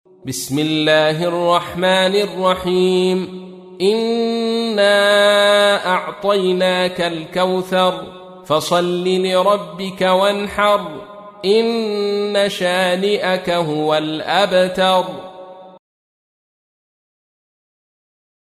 تحميل : 108. سورة الكوثر / القارئ عبد الرشيد صوفي / القرآن الكريم / موقع يا حسين